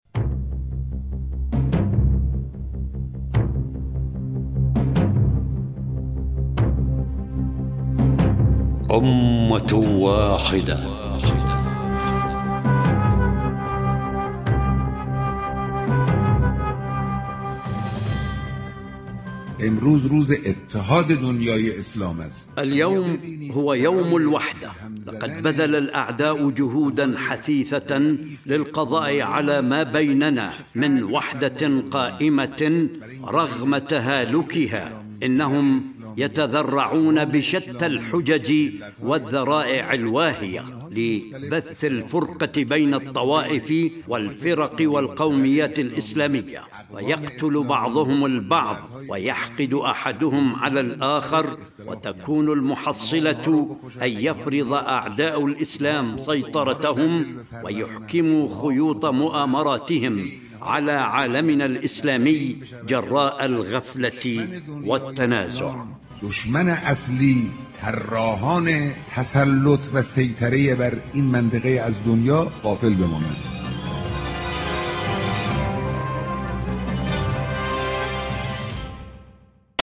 إذاعة طهران- أمة واحدة: الحلقة 7- كلمات قائد الثورة الإسلامية الإمام الخامنئي حول الوحدة الإسلامية.